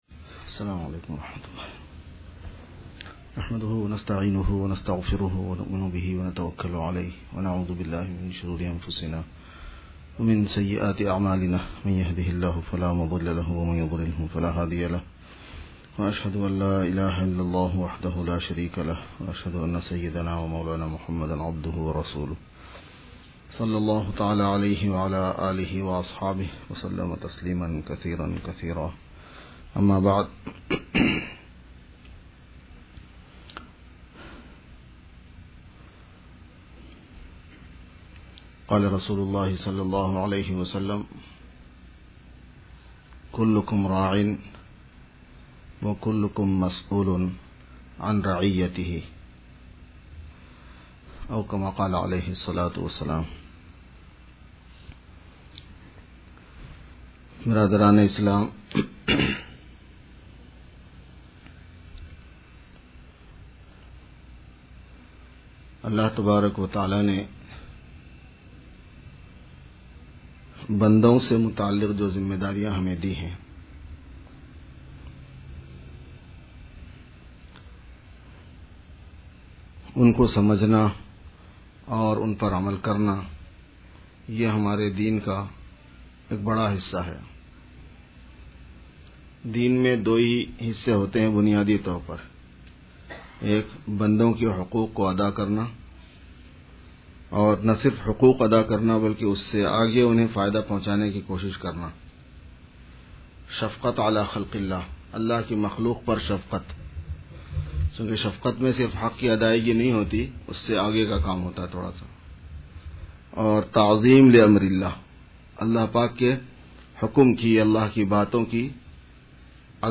Khutbat e Juma